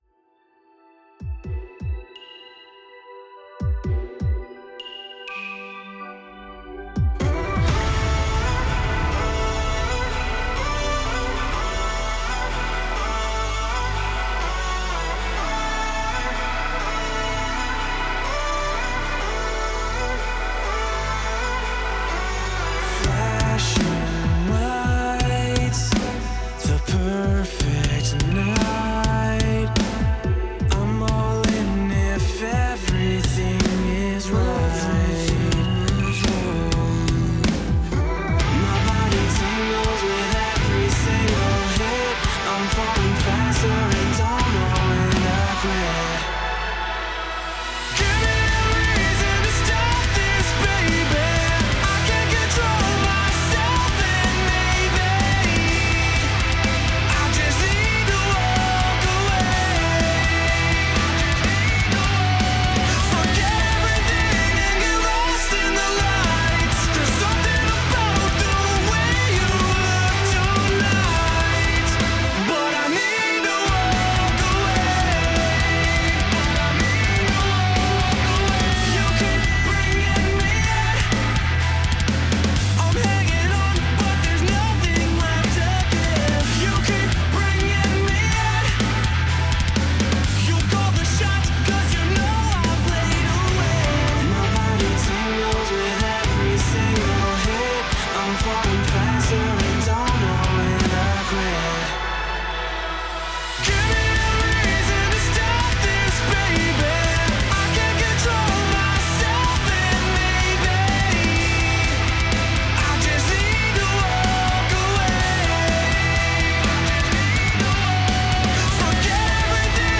Reamping, Mixing, Mastering